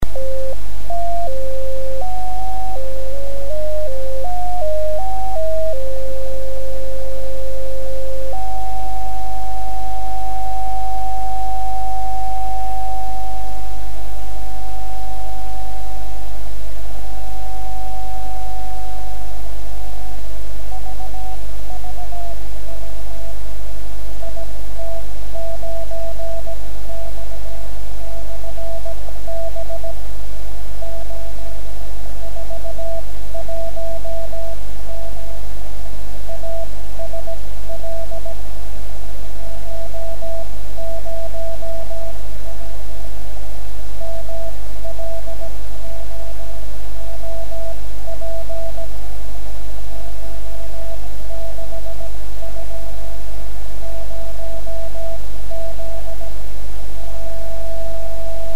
Die hier aufgeführten Stationen wurden von mir selbst empfangen.